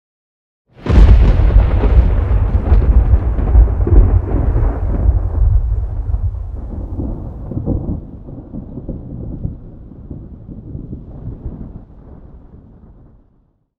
thunder-2.ogg